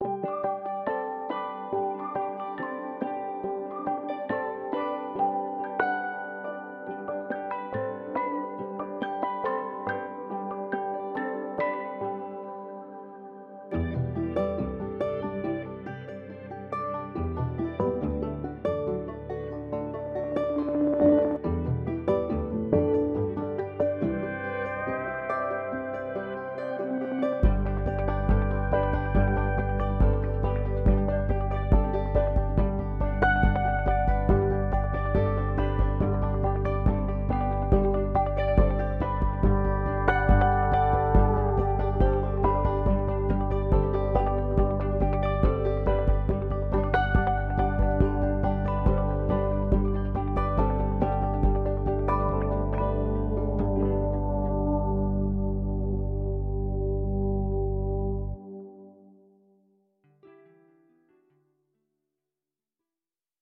- 包含 40 种不同的乐器，从自弹琴到拇指琴，从音乐盒到踏板钢琴，等等
- 每次按下一个键，都会随机播放一个乐器，或者您可以控制随机程度
- 可以使用鸭子效果、瞬态效果、反向效果和色彩效果，增加声音的动感和个性